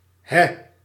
Ääntäminen
IPA: /ɦɛ/